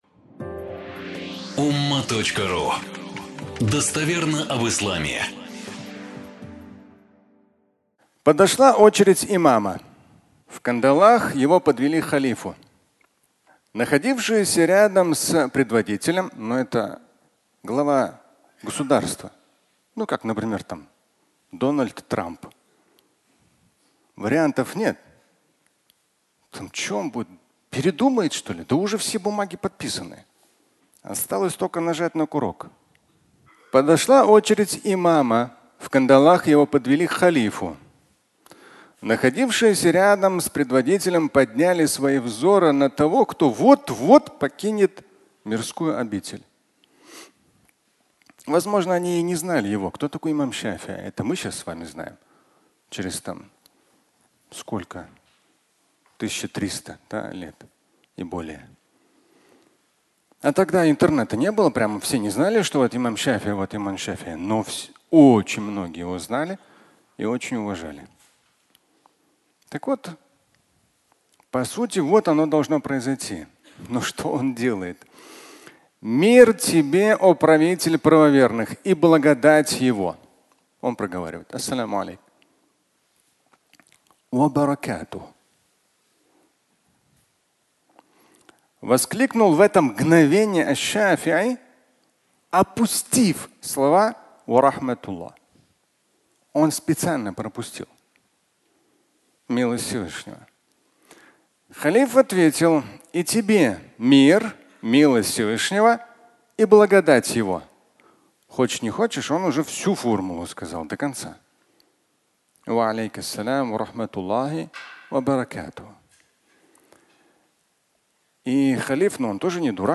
Жертва науки. Часть 2 (аудиолекция)